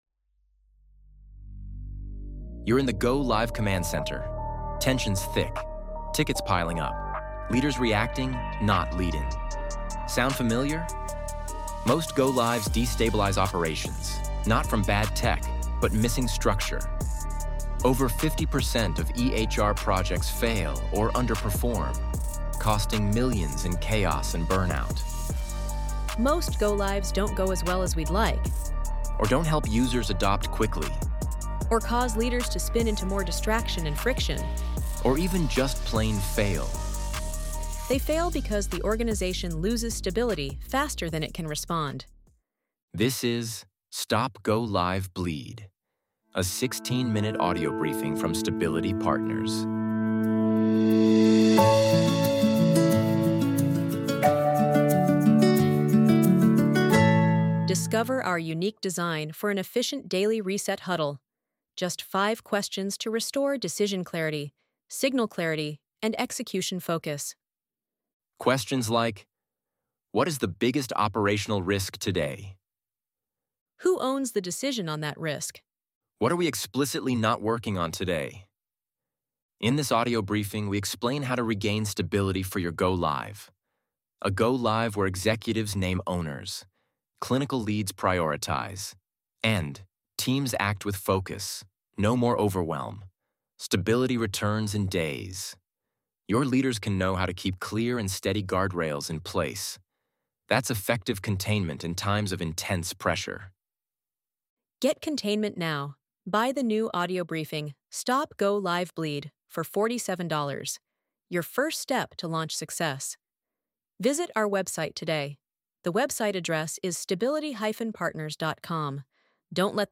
An Audio Briefing
PLAY NOW -- Audio Briefing Trailer (3 Minutes)